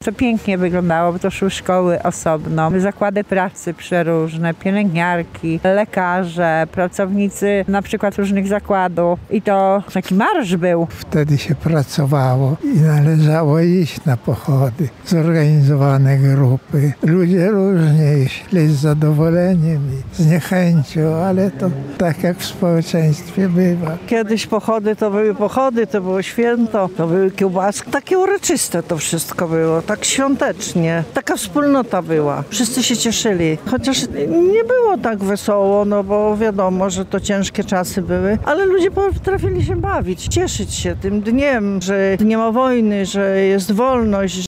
Podczas uroczystości na placu Litewskim wspominali, jak kiedyś obchodzono 1 maja.